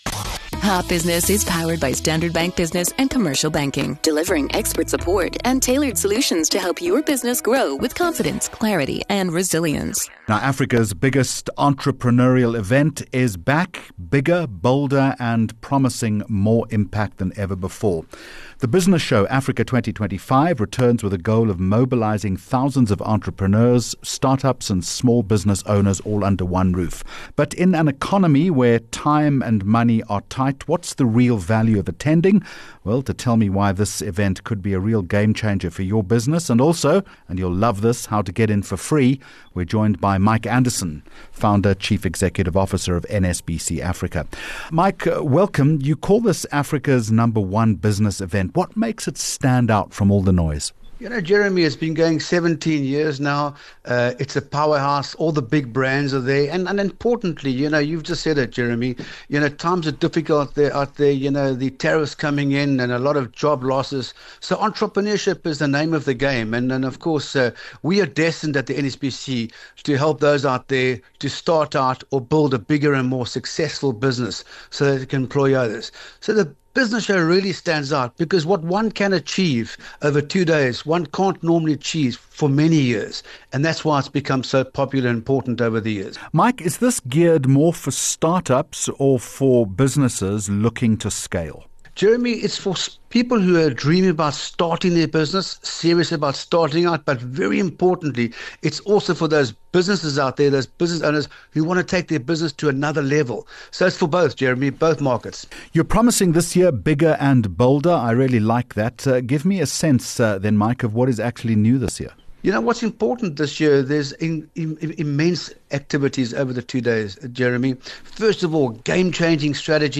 6 Aug Hot Business Interview